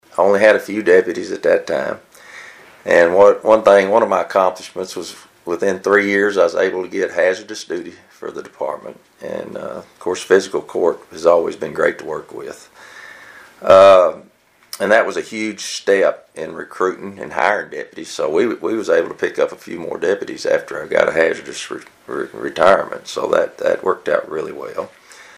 Sheriff Hudson tells the News Edge when he was first elected in 1998 he had a vision for the department that was a huge factor in recruiting new deputies.